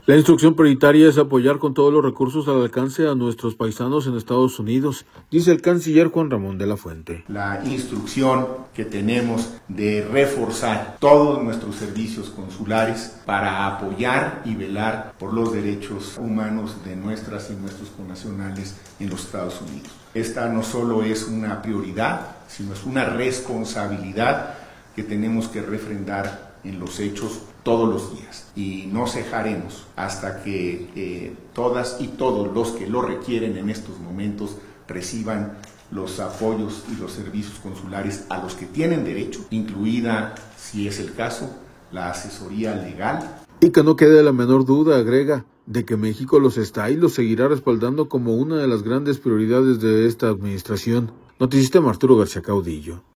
La instrucción prioritaria es apoyar con todos los recursos al alcance a nuestros paisanos en Estados Unidos, dice el Canciller Juan Ramón De la Fuente.